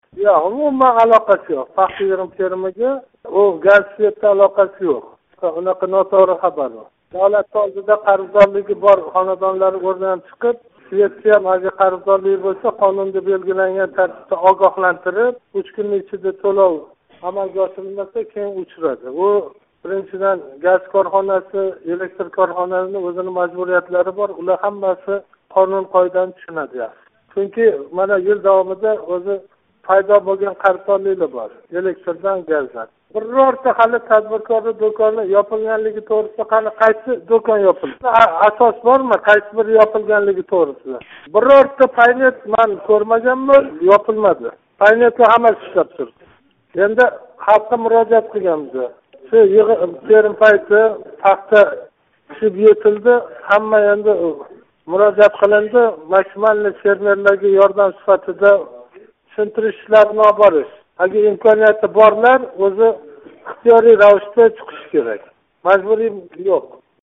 Чиноз тумани ҳокими Маҳмуд Эшонов билан суҳбат